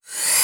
VEC3 Reverse FX
VEC3 FX Reverse 05.wav